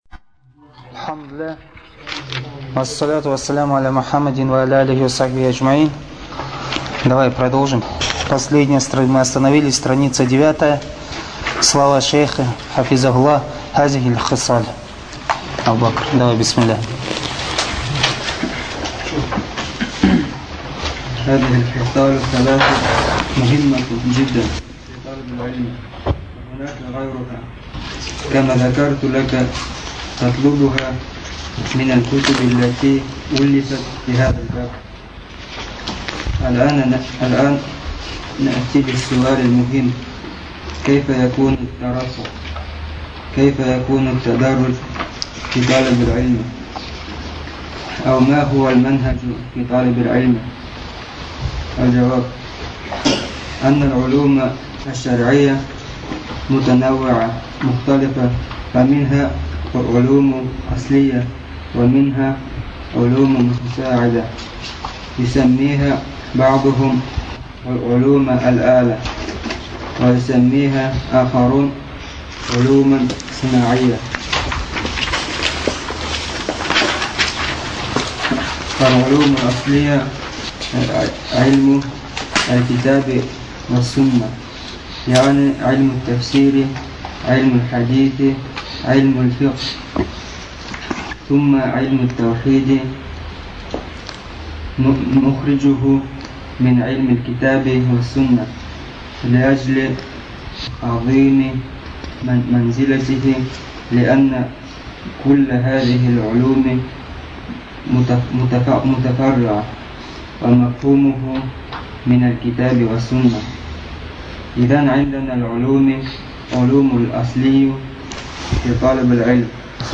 Материал:Лекция шейха Салиха бин АбдульАзиз Содержание:описание каким образом следует требовать знания